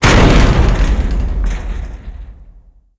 HitConcussion.wav